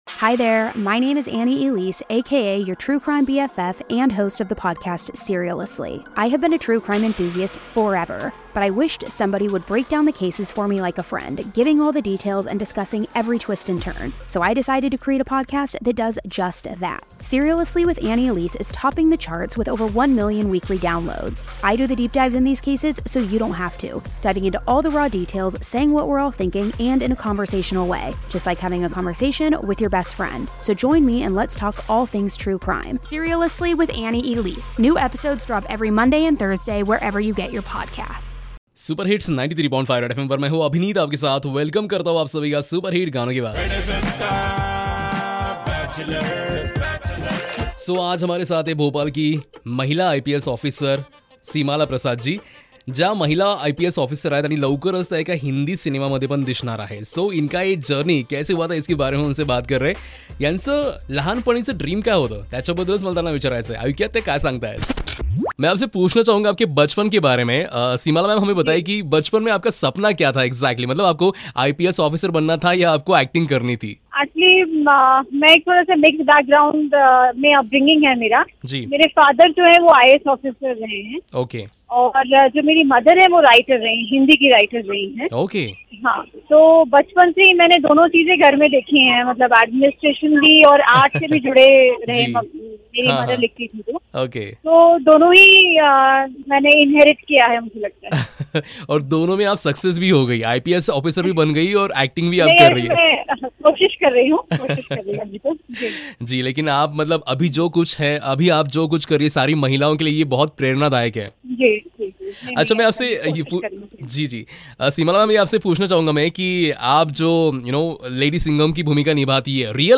taking an interview of IPS OFFICER, seemala prasad.